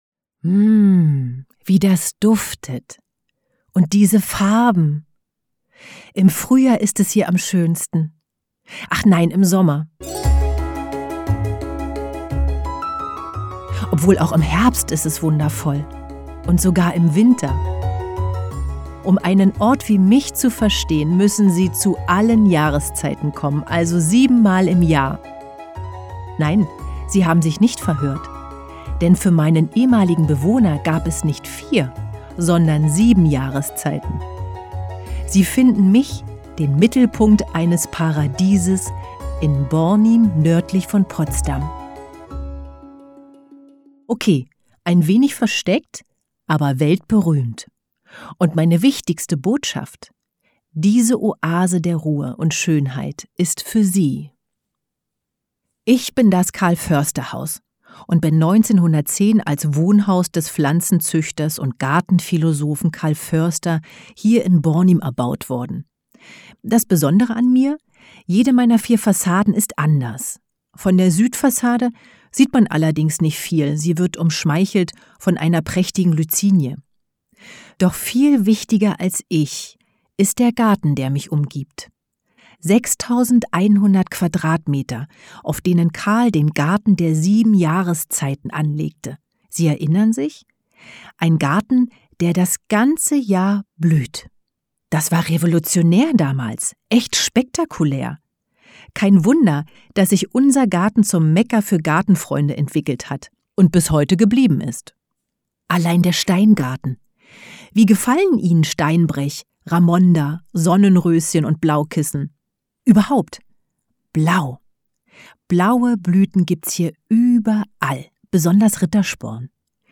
Booking Sprecherin